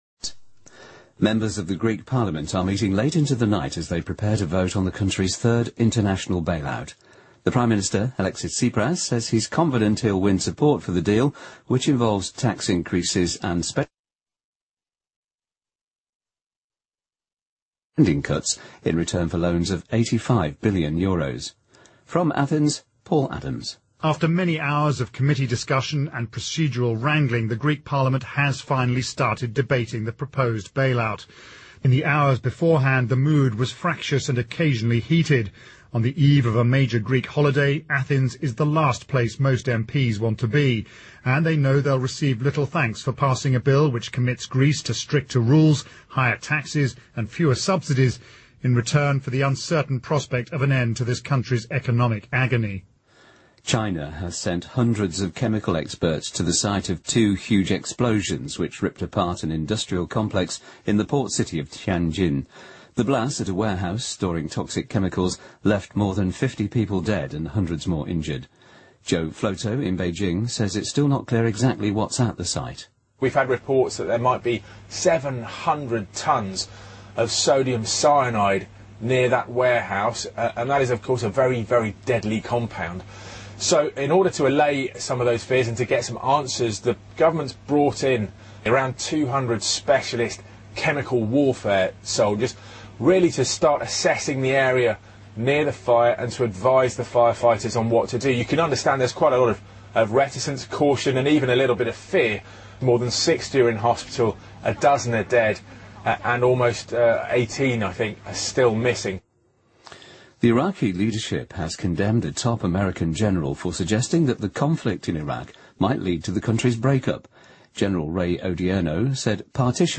BBC news,古巴前总统卡斯特罗指责美国欠古巴数百万美元
日期:2015-08-16来源:BBC新闻听力 编辑:给力英语BBC频道